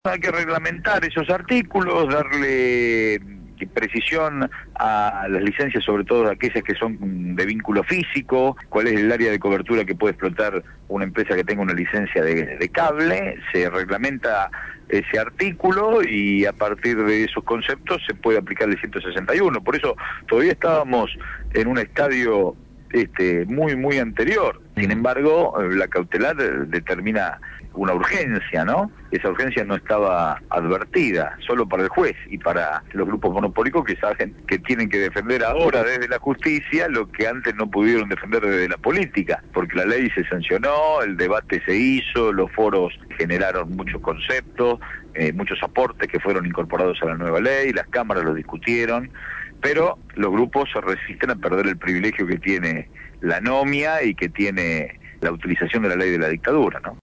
Gabriel Mariotto, Presidente de la Autoridad de Apliación de Servicios de Comunicación Audiovisual, habló en «Desde el Barrio» sobre la consideración de inconstitucionalidad de los Articulos 41 y 161 de la Ley de Medios dictada por el Juez Eduardo Carbone.